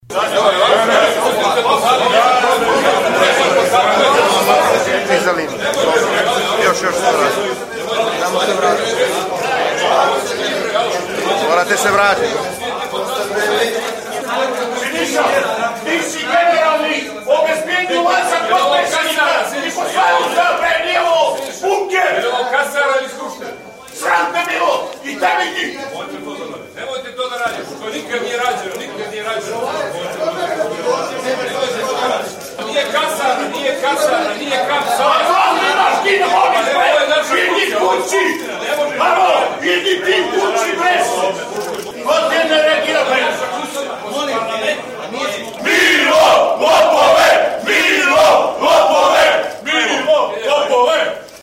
Prilikom ulaska u parlament poslanika Demokratskog fronta, koji su mjesecima unazad bojkotovali rad parlamenta, došlo je do incidenta sa skupštinskim obezbjeđenjem, jer su poslanici DF-a odbili da prođu kroz metal detektor.
Uz glasno negodovanje, guranje i koškanje i glasne psovke, poslanici DF-a su stali u sredinu hola, razvili transparent i skandirali "Milo lopove".